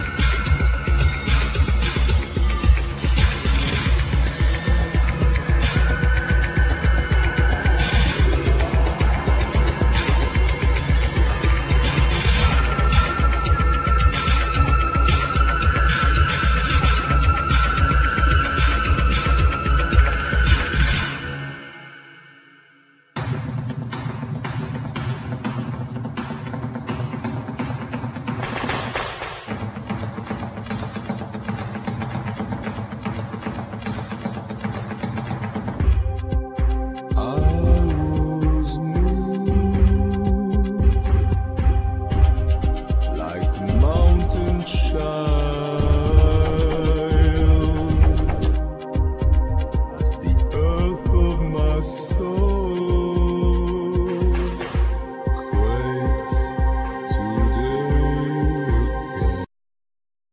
Machines,Clarinet
Machines,Vocals,Accordion
Sweet tribal drums
Trumpet
Electric bow,Slide guiter,Iron guitar
Electric guitar effects
Analog vintage synths